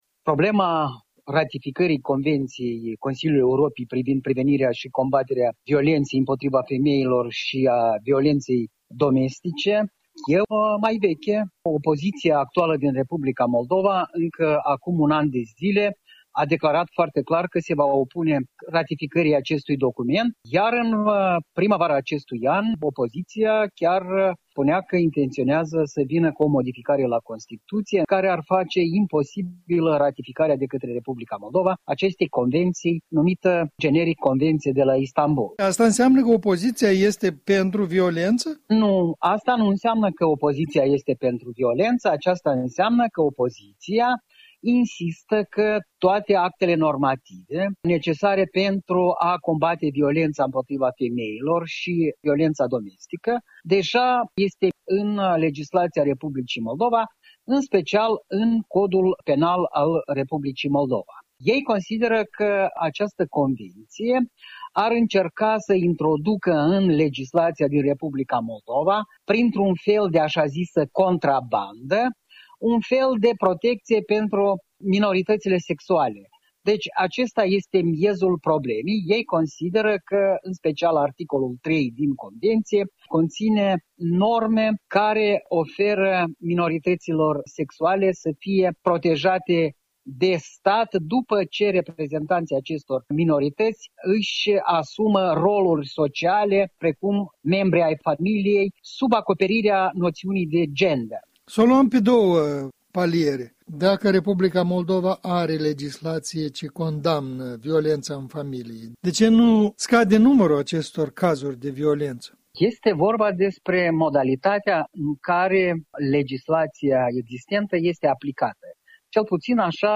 stă de vorbă cu analistul politic